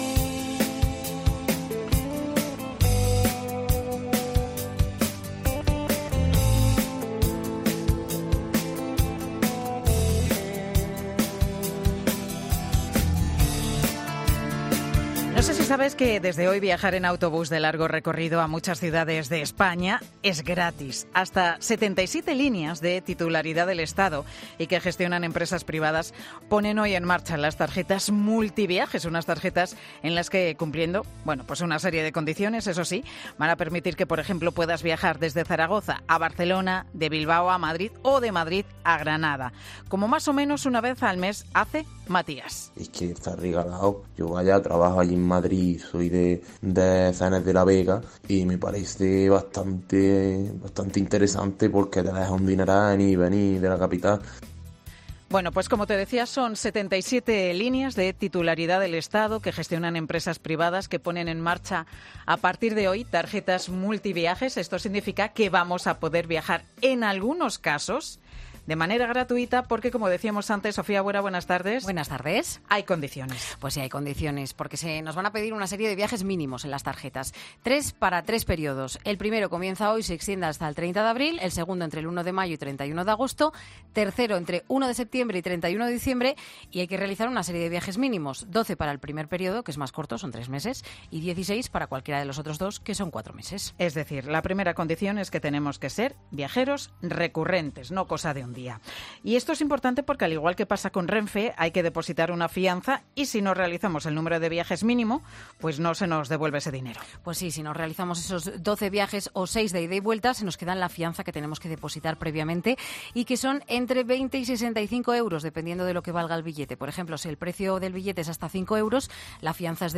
COPE en la estación de autobuses de Santiago: "Mucha gente ha pagado el billete íntegro"